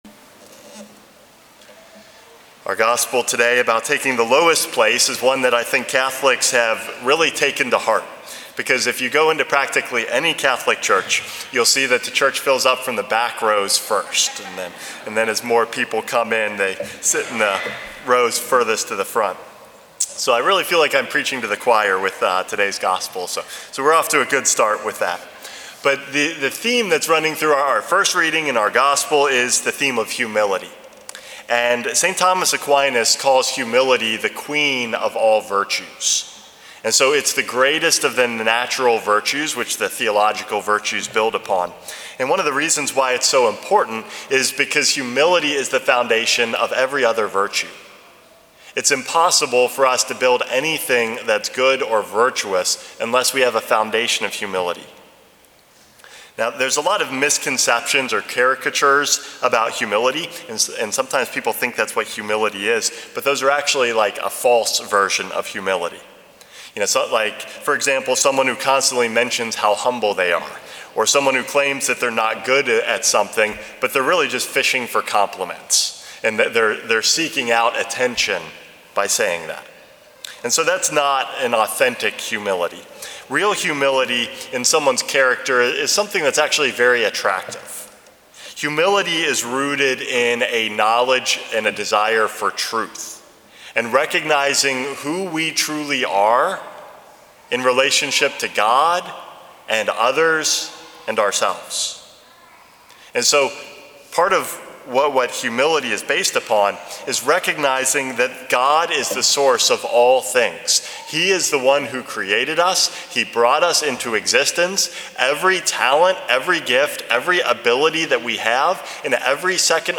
Homily #462 - Taking the Back Pew